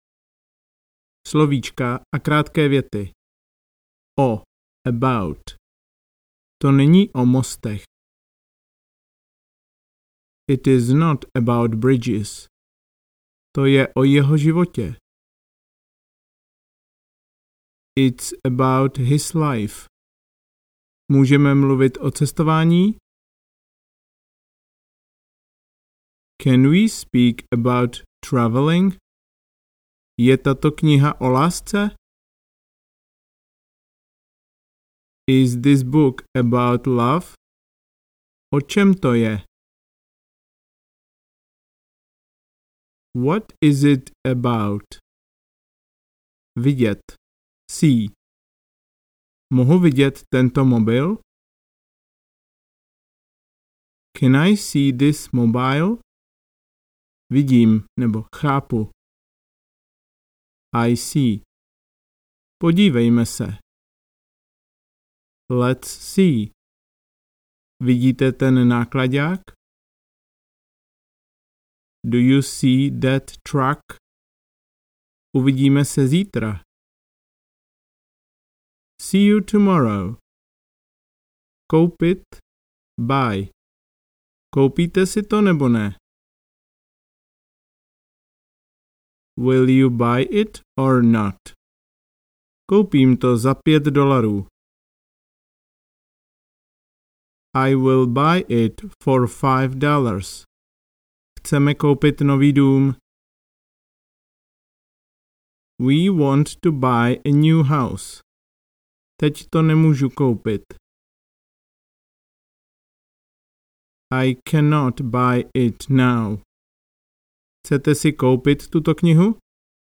Začátečník na cestách audiokniha
Ukázka z knihy